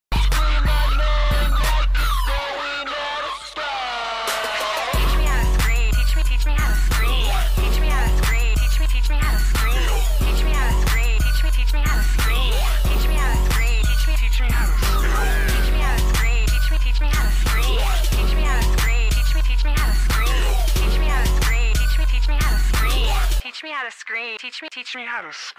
Yawn so bored.. ꒷꒦︶꒷꒦︶ ๋ sound effects free download